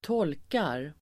Uttal: [²t'ål:kar]